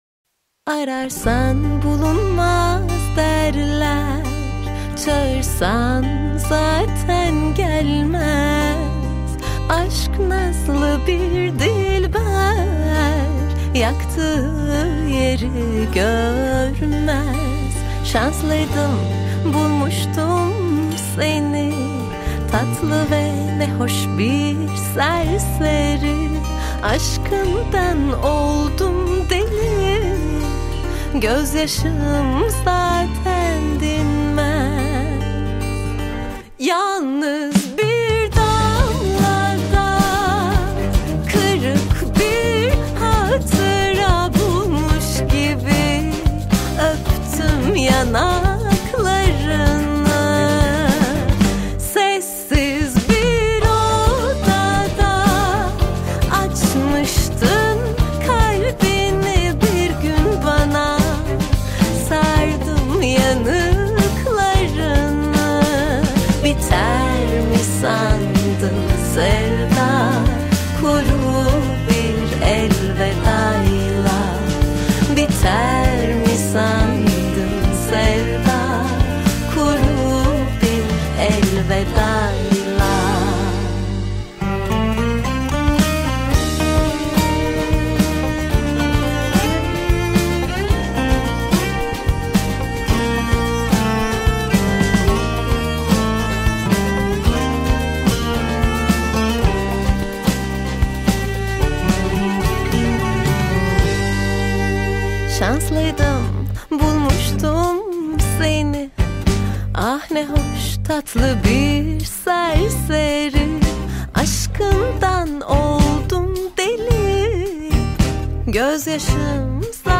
Жанр: Турецкие песни